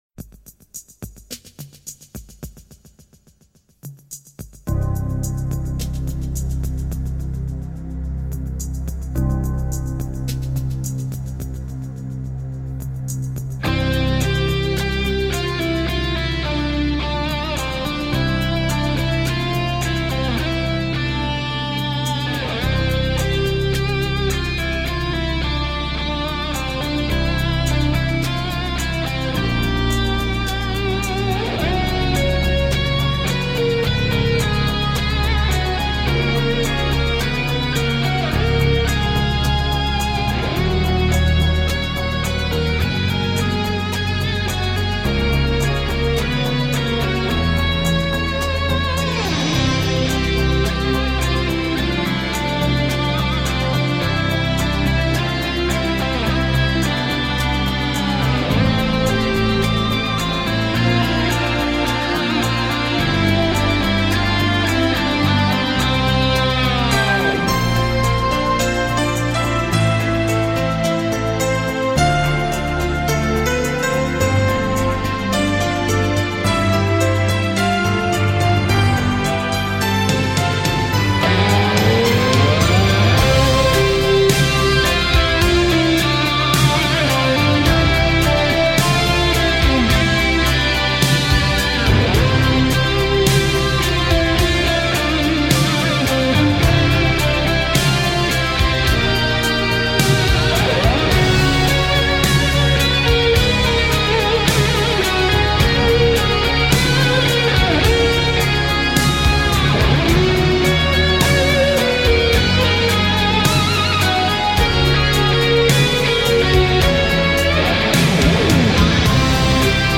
Chantez synthés, résonnez guitares !
Vrombissant.